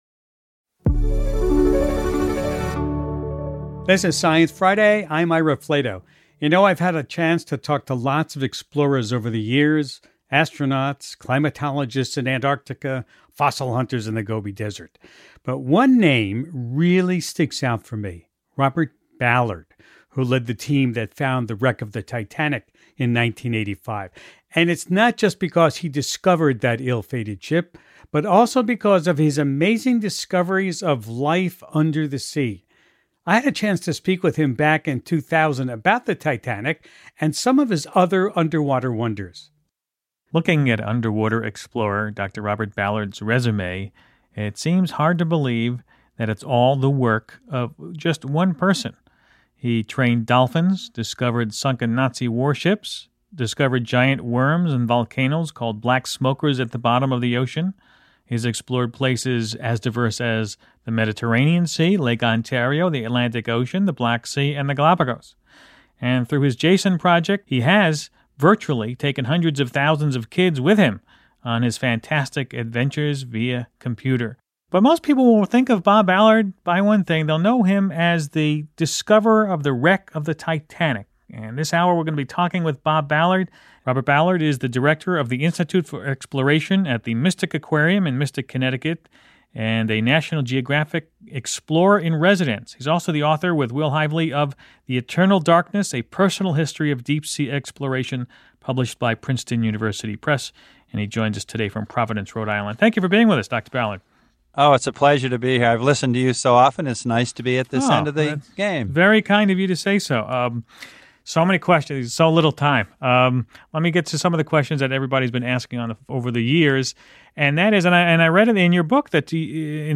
In excerpts of two conversations from the Science Friday archives (originally recorded in 2000 and 2009), oceanographer Robert Ballard joins Host Ira Flatow to discuss the 1985 expedition in which he discovered the wreck of the Titanic. He also emphasizes the value of combining the efforts of oceanographers, engineers, and social scientists to study the world’s deep oceans.